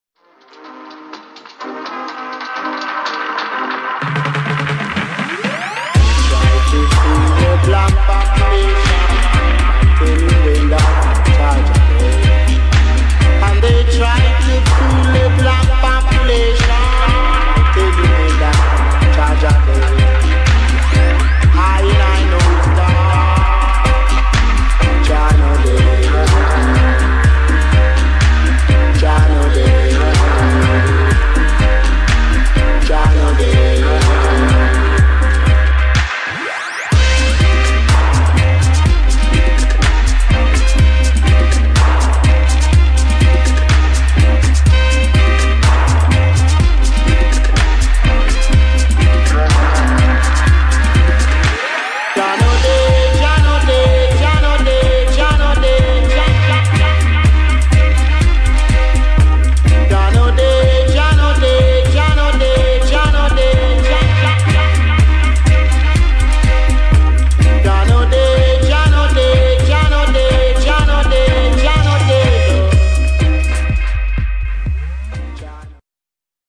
REGGAE / DUB / DUBSTEP